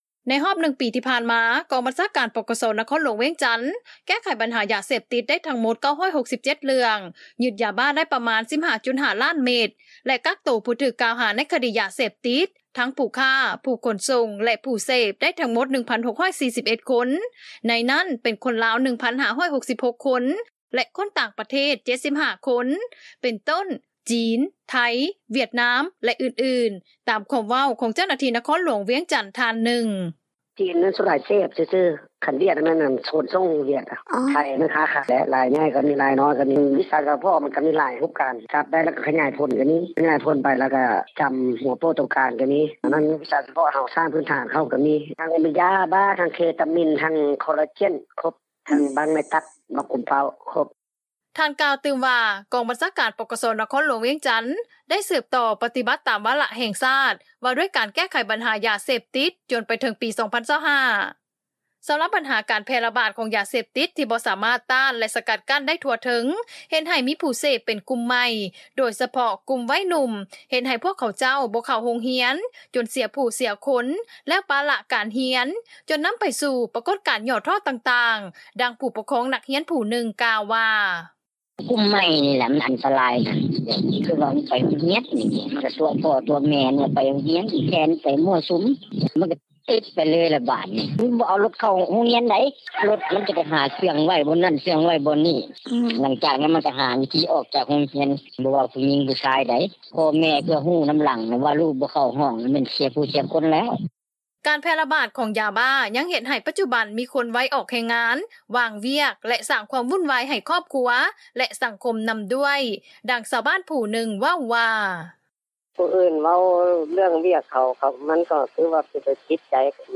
ການແຜ່ລະບາດ ຂອງຢາບ້າ ຍັງເຮັດໃຫ້ປັດຈຸບັນ ມີຄົນໄວອອກແຮງງານ ວ່າງວຽກ ແລະສ້າງຄວາມວຸ້ນວາຍໃຫ້ຄອບຄົວ ແລະສັງຄົມນໍາດ້ວຍ, ດັ່ງຊາວບ້ານຜູ້ໜຶ່ງ ເວົ້າວ່າ:
ນອກຈາກນັ້ນ ອໍານາດການປົກຄອງບ້ານ ກໍ່ຕ້ອງເປັນເຈົ້າການ ໃນການສຶກສາອົບຮົມພົນລະເມືອງ ພາຍໃນບ້ານ ທີ່ເປັນຜູ້ຕິດຢາເສບຕິດ ໃຫ້ເຊົາເສບຢ່າງເດັດຂາດ ເພື່ອບໍ່ໃຫ້ຜູ້ເສບຢາເສບຕິດ ໄປສ້າງຄວາມວຸ້ນວາຍໃຫ້ຄອບຄົວ ແລະເປັນໄພສັງຄົມ, ດັ່ງນາຍບ້ານ ທ່ານໜຶ່ງ ກ່າວວ່າ: